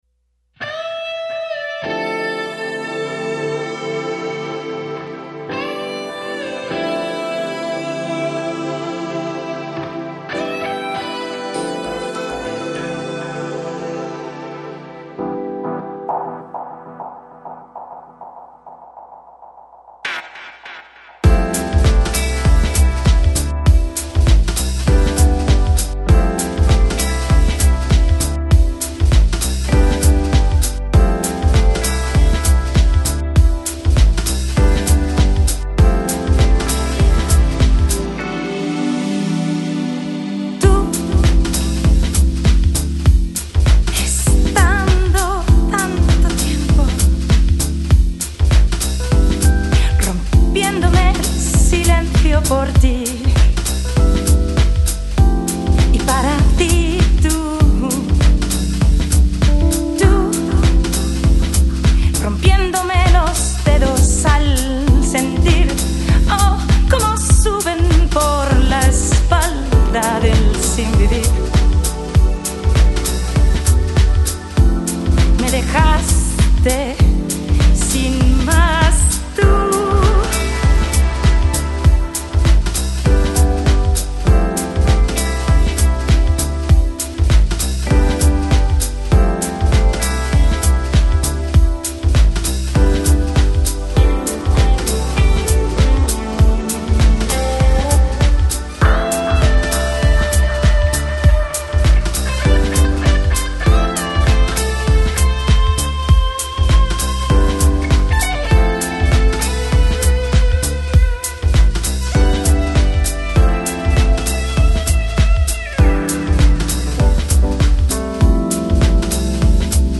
Жанр: Lounge | Chillout | Nu Jazz